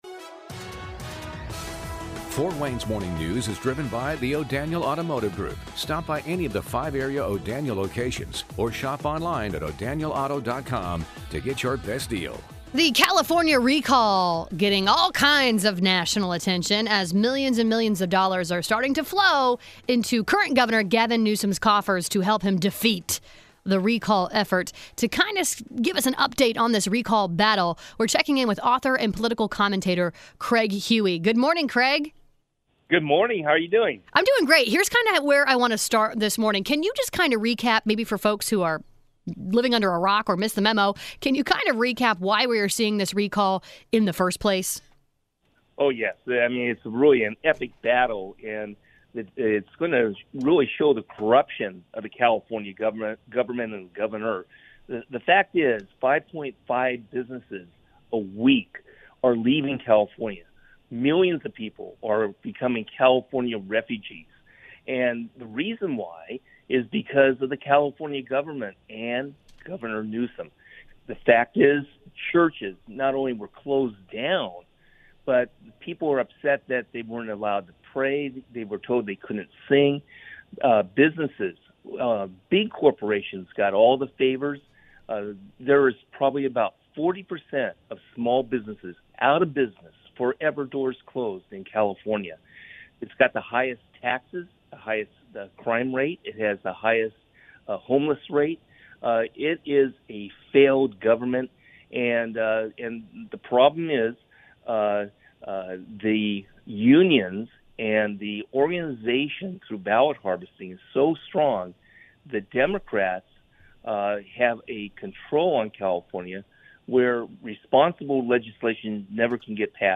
The interview is about 7-minutes long